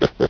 animalia_pig_idle.ogg